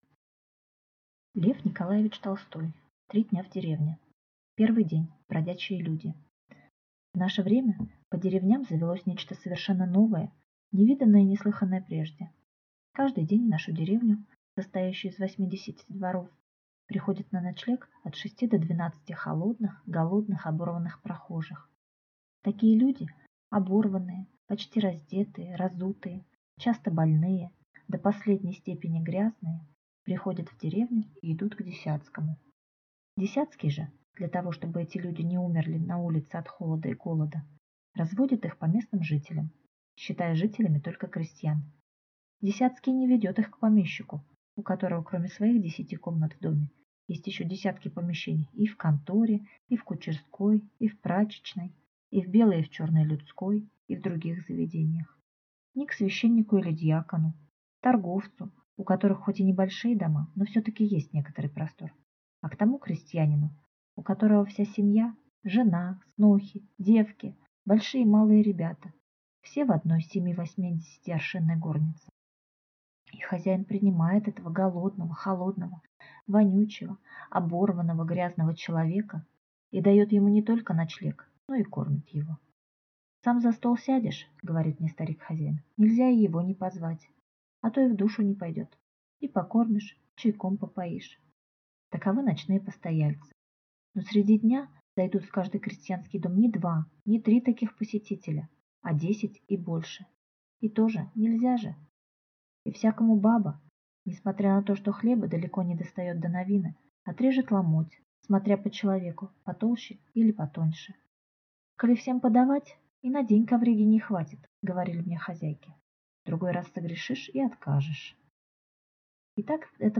Аудиокнига Три дня в деревне | Библиотека аудиокниг